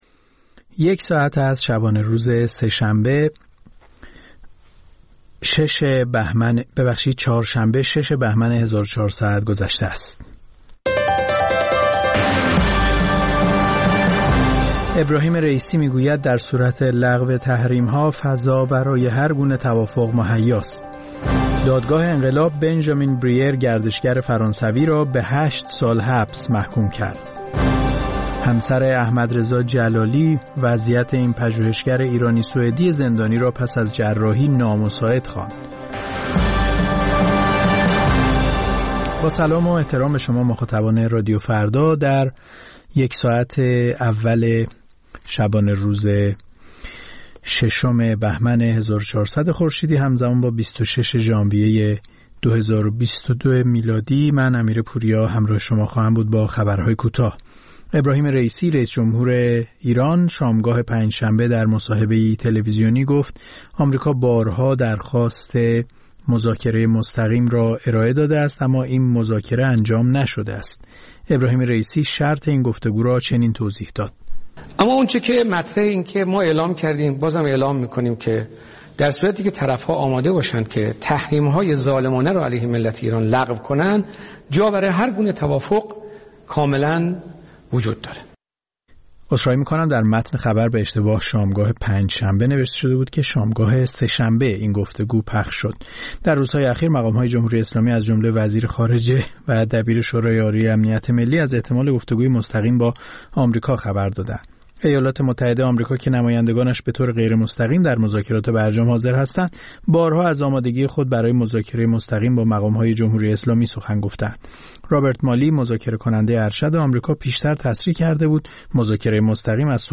سرخط خبرها ۱:۰۰